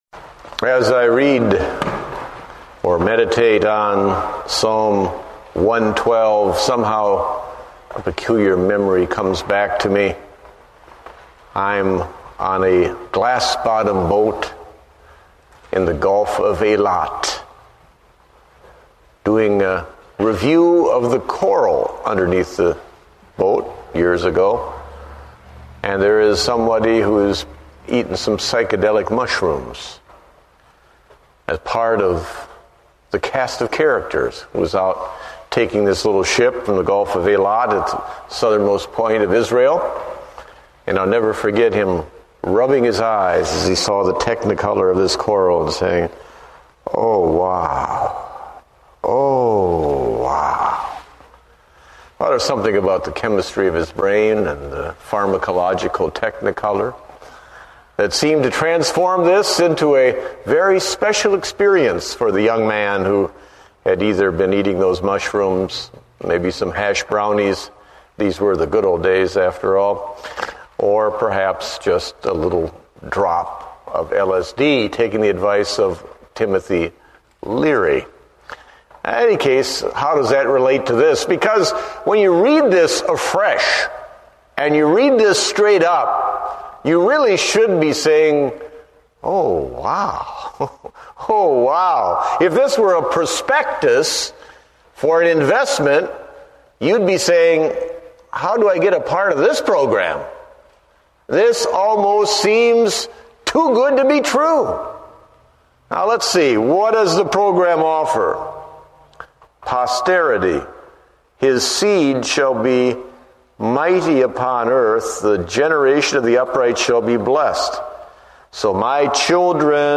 Date: August 24, 2008 (Evening Service)